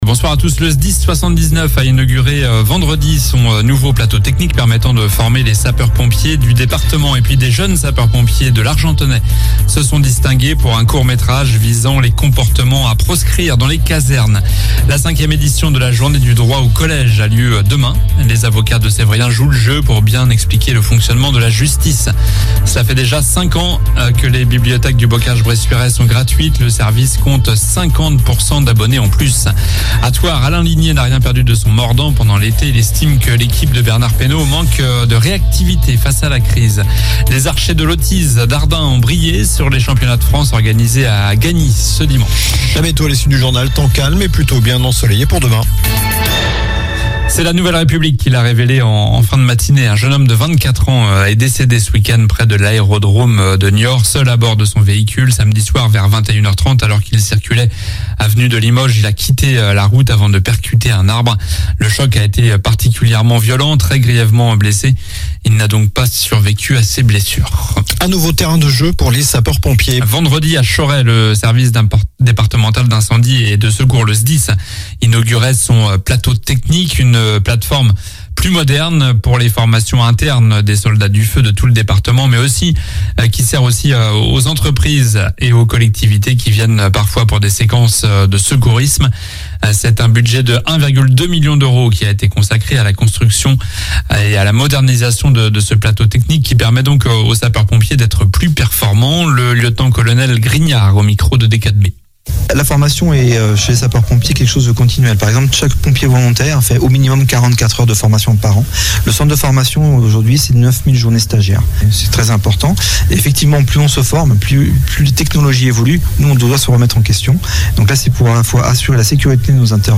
Journal du lundi 03 octobre (soir)